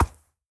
horse_soft6.ogg